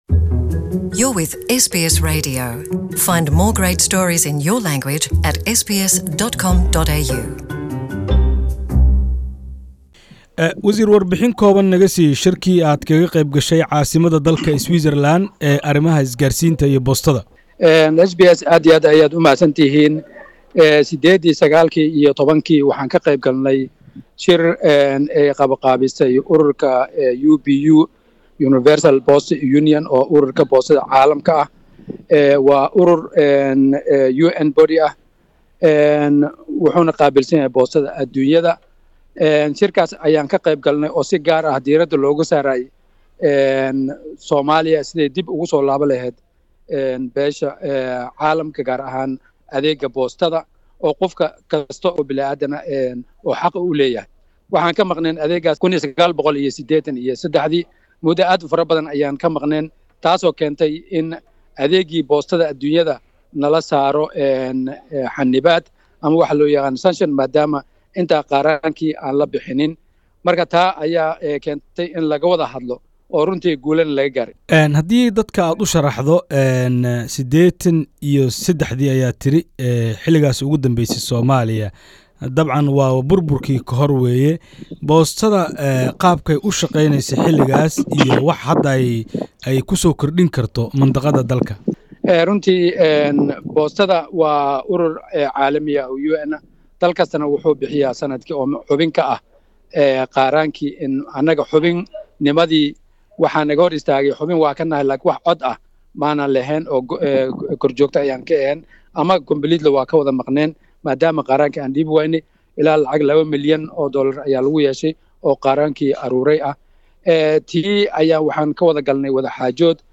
Waraysi: Wasiirka boostada iyo isgaadhsiinta Soomaaliya oo sheegay in adeega boostada uu mar dhaw Soomaaliya dib ooga bilaaban doono.
Interview: Somali Minister of Postal and Telecommunication, promised the postal service in Somalia to be implemented soon.